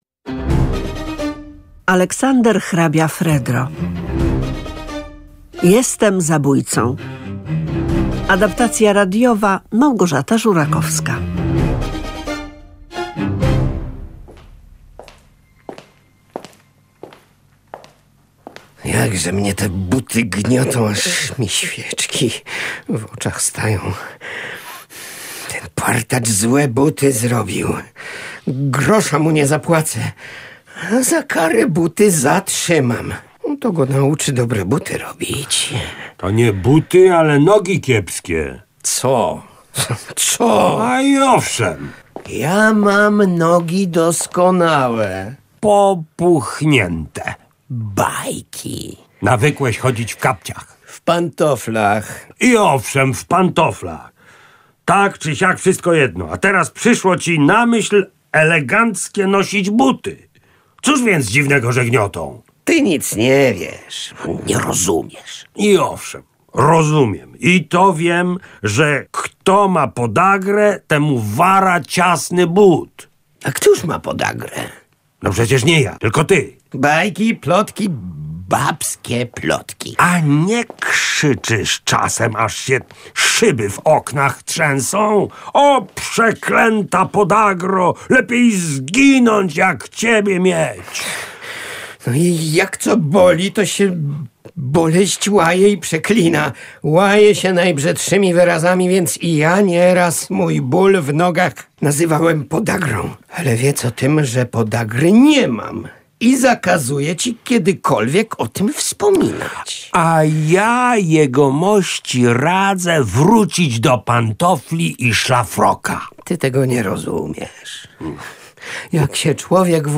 W programie słuchowisko na podstawie mało znanej komedii Aleksandra hrabiego Fredry „Jestem zabójcą” – o starszym opiekunie, który koniecznie chce się ożenić ze swoją podopieczną….